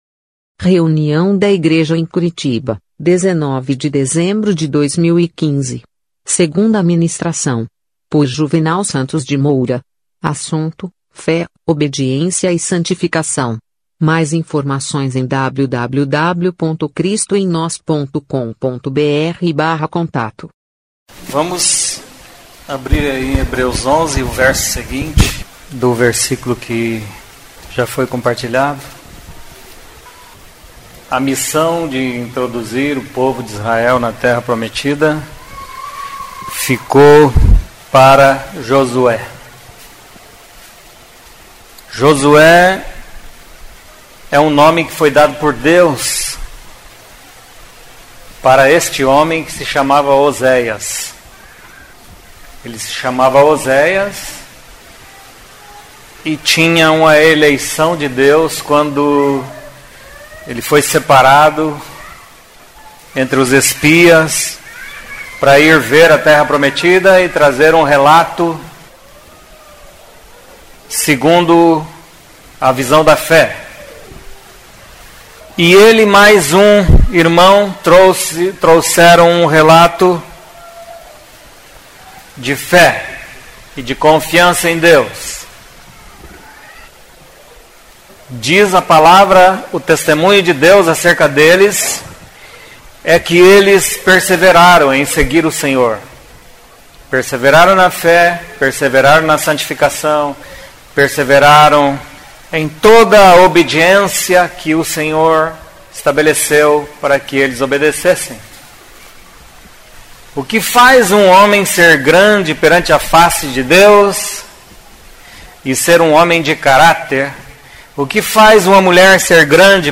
Segunda mensagem
da reunião da igreja em Curitiba do dia 19/12/2015.